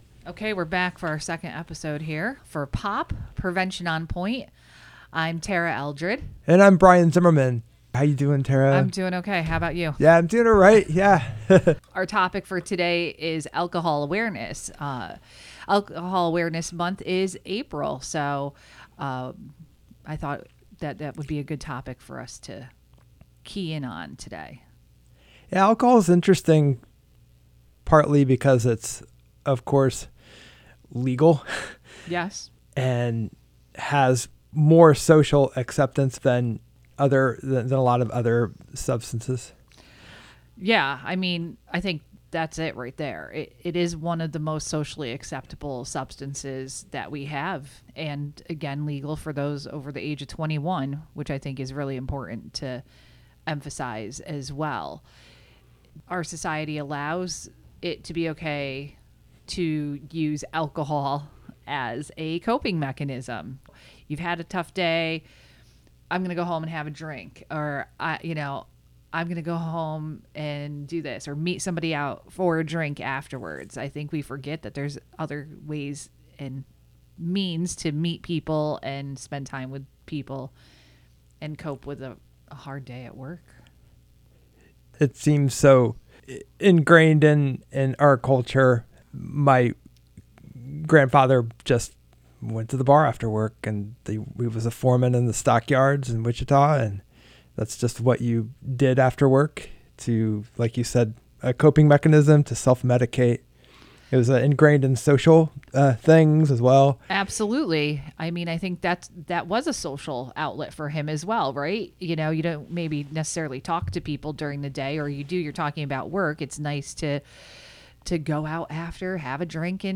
Lively conversation and useful information about substance use and misuse, prevention, wellness, and community.